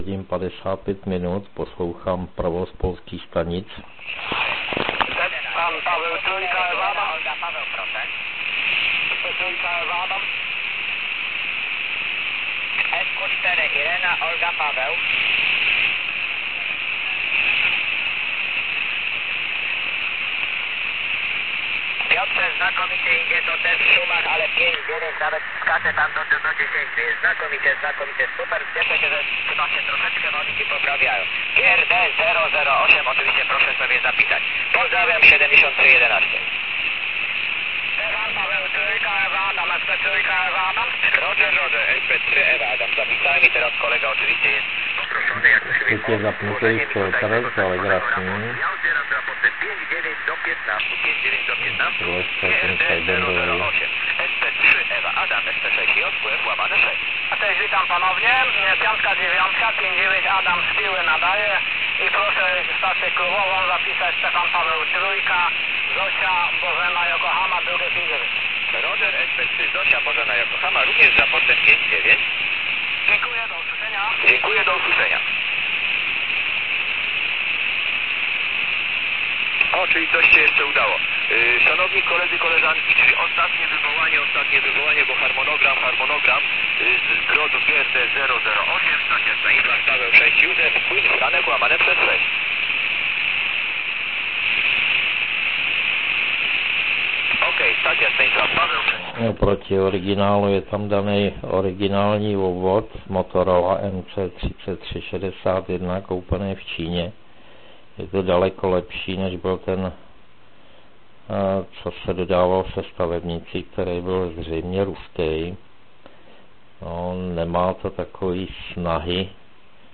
Celkem bez větších problémů jsem v poledních hodinách poslouchal i SP stanice.